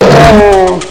zombieman_die.wav